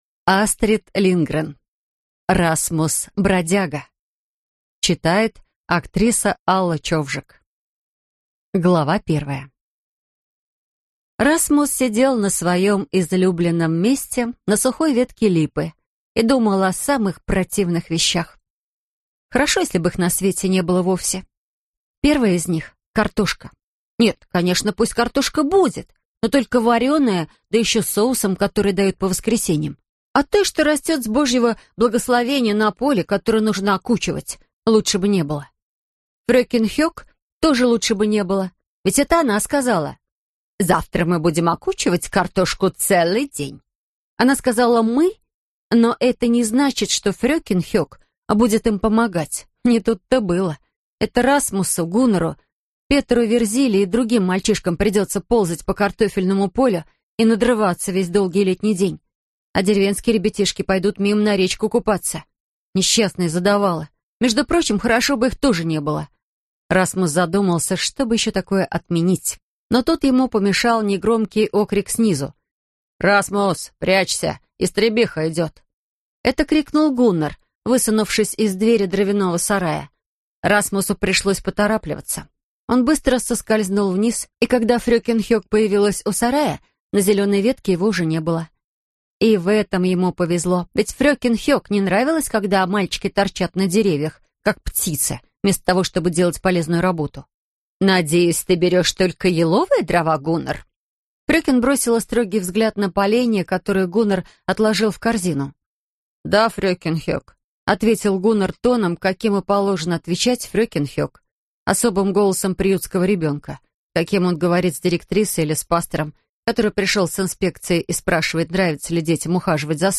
Аудиокнига Расмус-бродяга - купить, скачать и слушать онлайн | КнигоПоиск